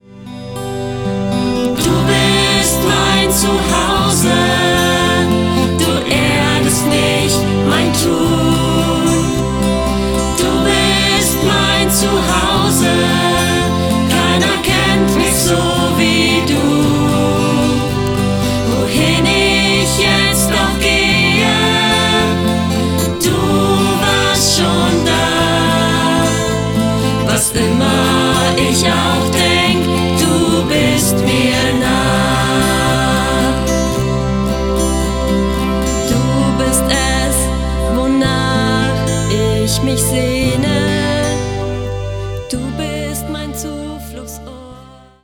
Genre: NGL.